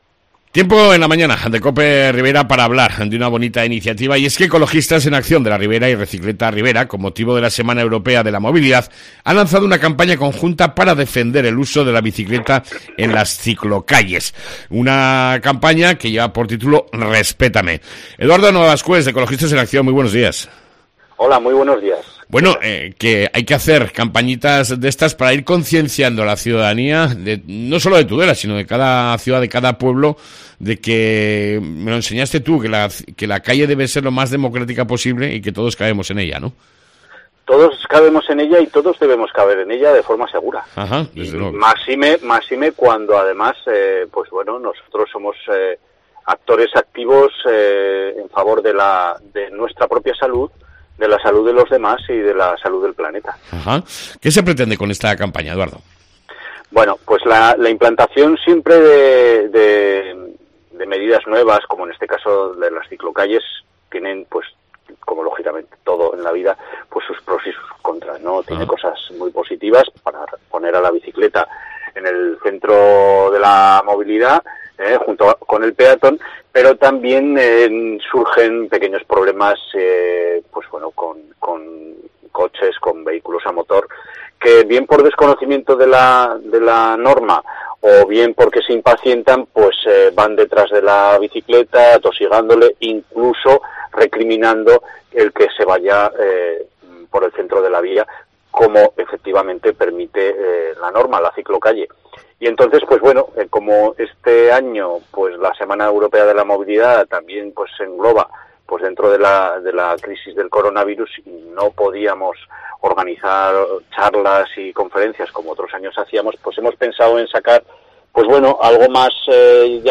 AUDIO: Entrevista